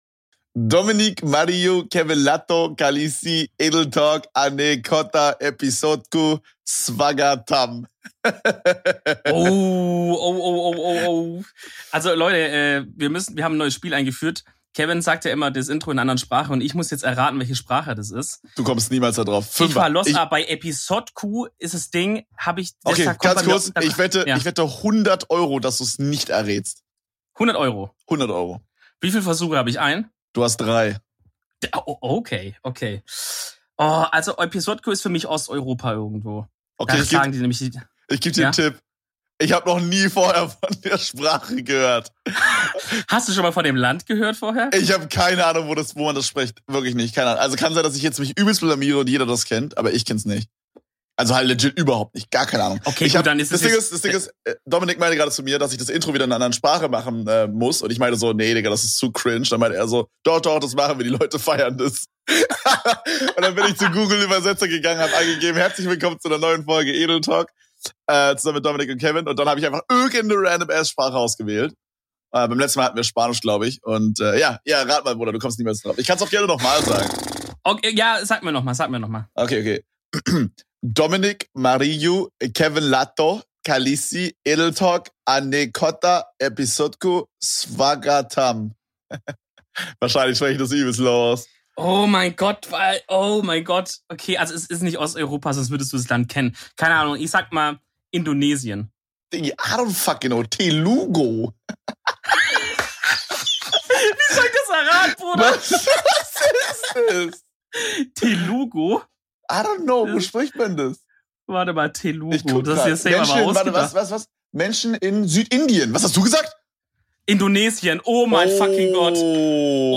In dieser Folge wird überdurchschnittlich viel Schweizerdeutsch gesprochen.